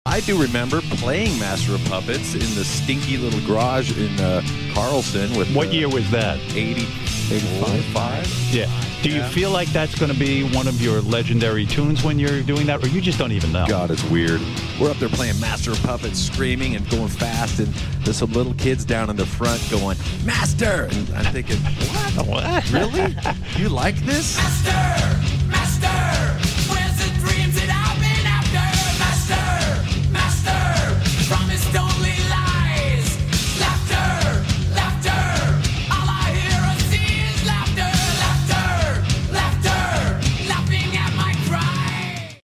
metal/rock